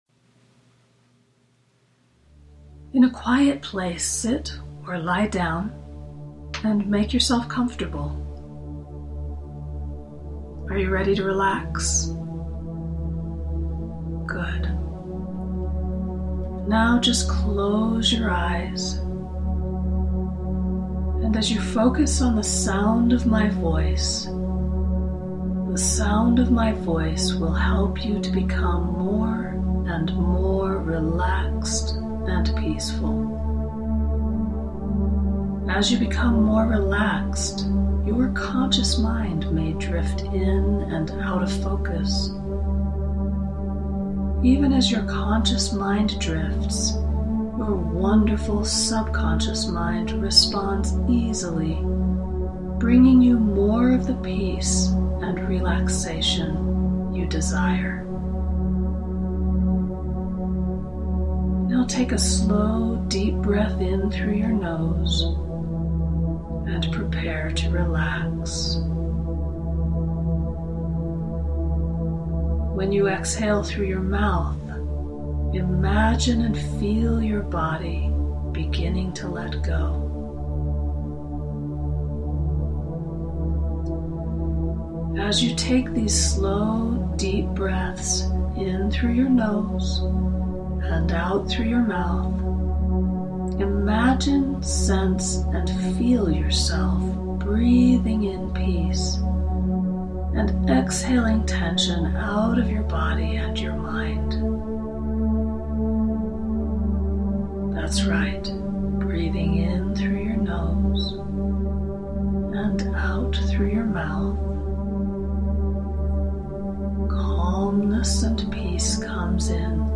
Calm-Cool-Connected-Relaxation-Meditation.mp3